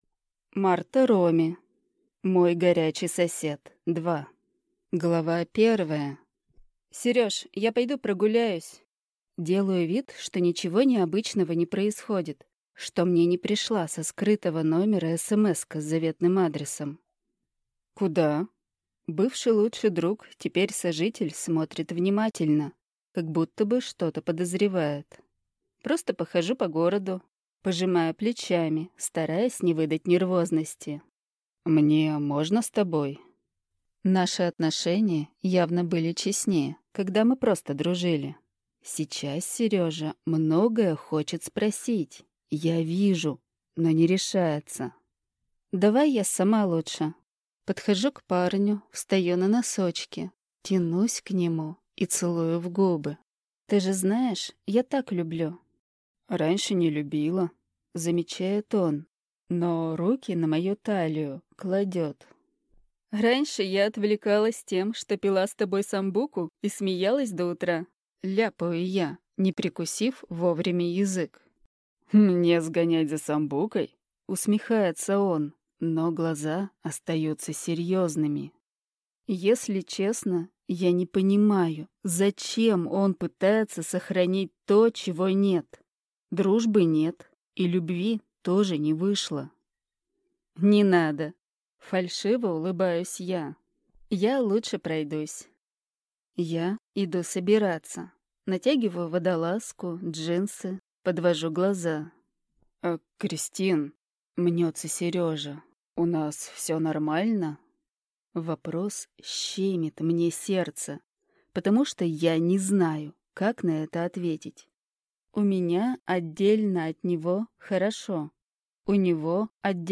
Аудиокнига Мой горячий сосед 2 | Библиотека аудиокниг